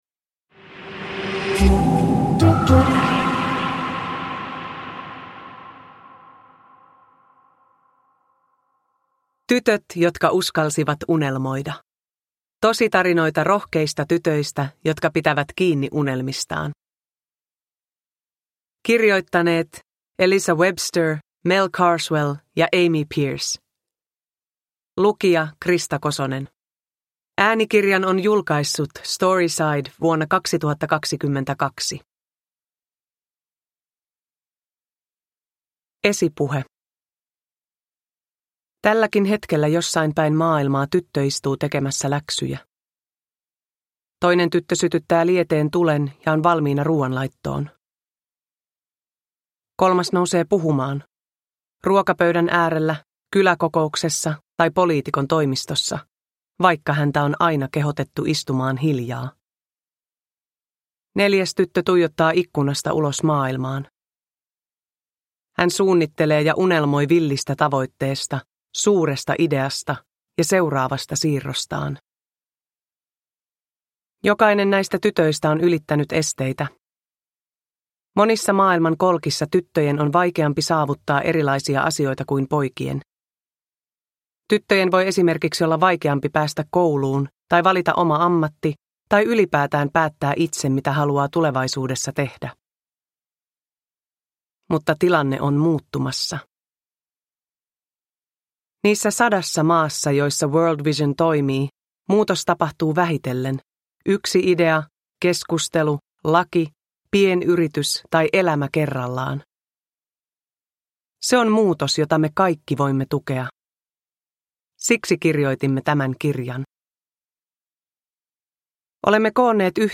Uppläsare: Krista Kosonen